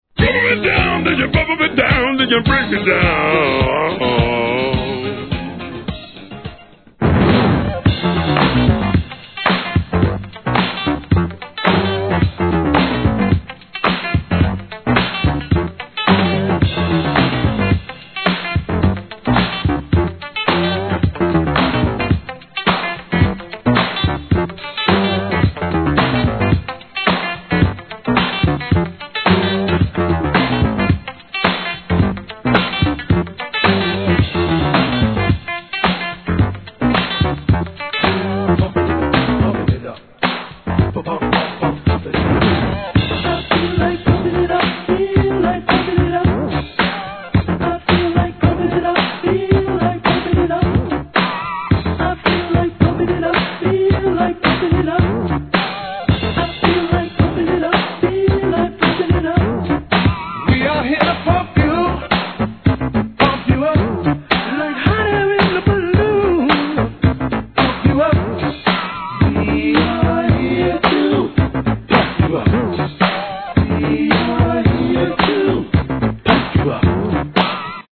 SOUL/FUNK/etc...